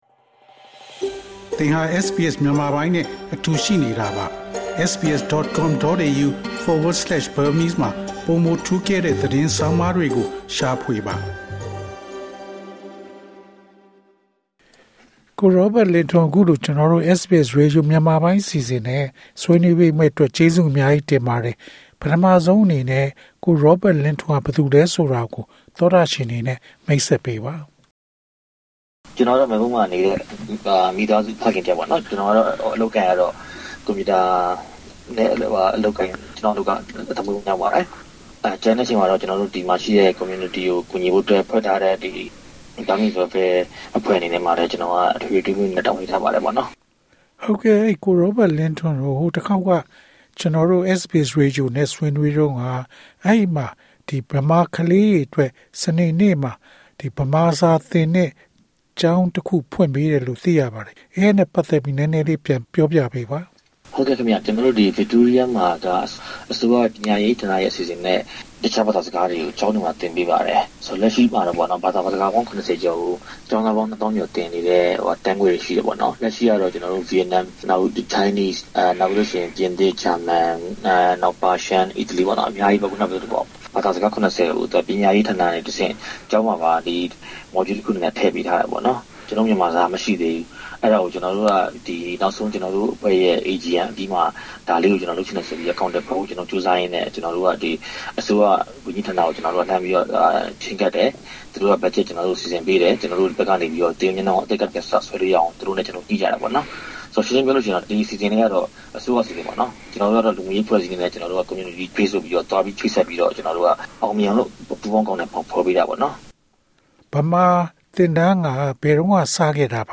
အင်တာဗျူး။